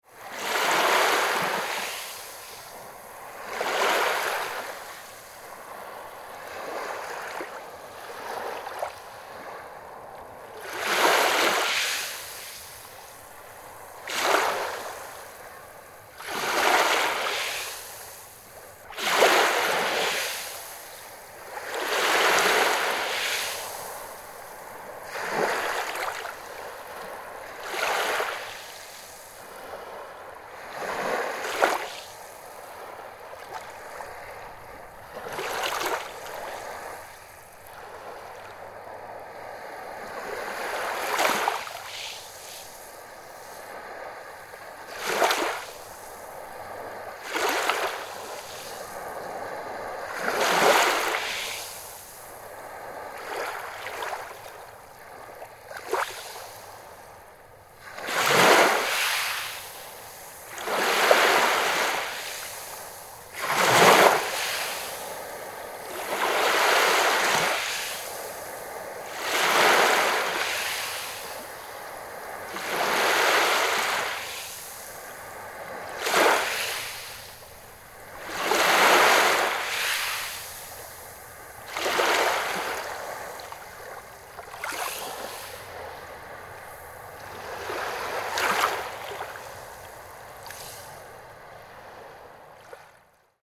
Gentle Relaxing Ocean Waves Hitting The Shore Sound Effect
Description: Gentle relaxing ocean waves hitting the shore sound effect.
Water sounds.
Genres: Sound Effects
Gentle-relaxing-ocean-waves-hitting-the-shore-sound-effect.mp3